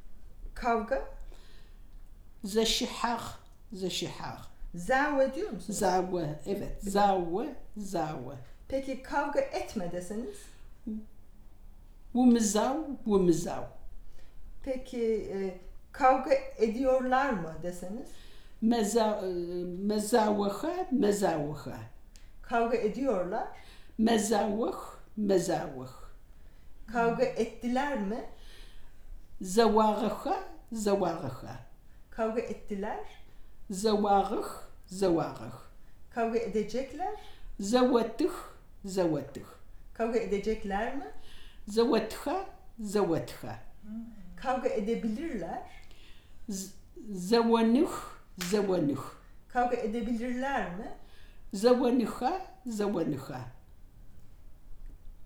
Elicited Verb paradigm
digital wav file recorded at 44.1 kHz/16 bit on Zoom H2 solid state recorder
Uzunyayla Turkey